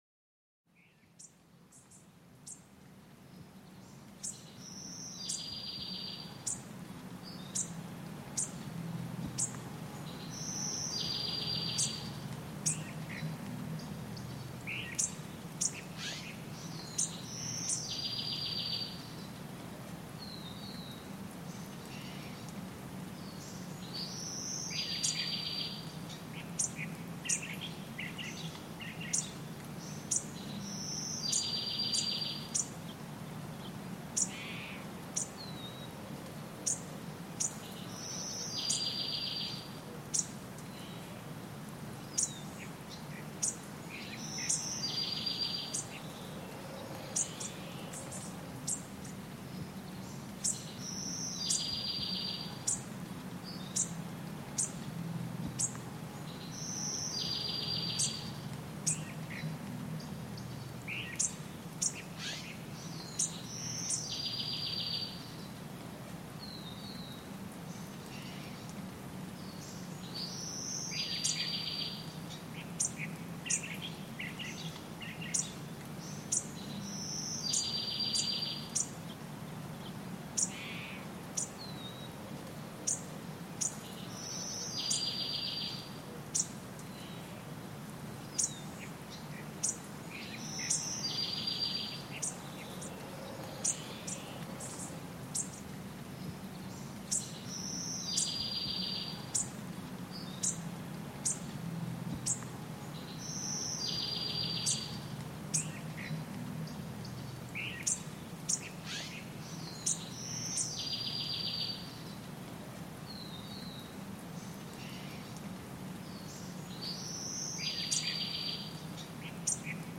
Chant d'oiseaux dans la forêt : un voyage apaisant pour l'esprit
Plongez dans une forêt enchantée où les chants mélodieux des oiseaux créent une symphonie naturelle parfaite. Chaque trille et chaque sifflement vous invitent à vous détendre et à vous reconnecter avec la sérénité de la nature.